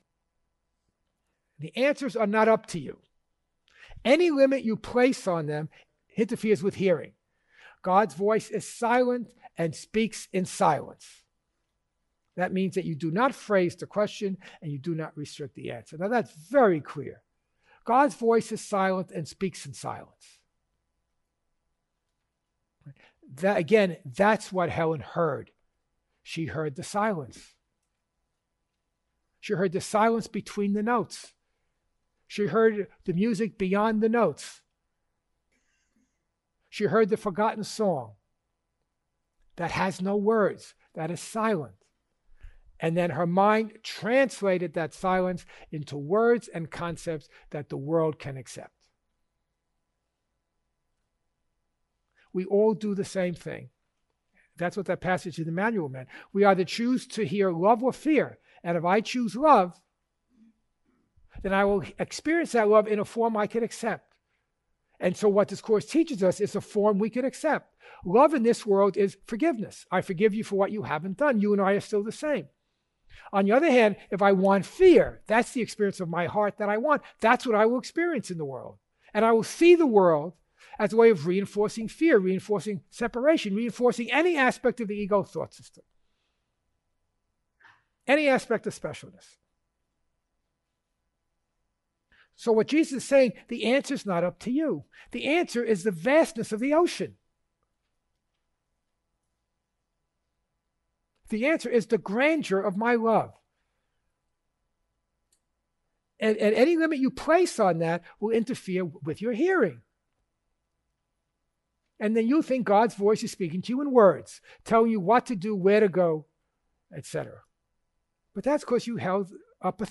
Original Workshop Dates: 11/2008 & 06/2006